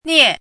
chinese-voice - 汉字语音库
nie4.mp3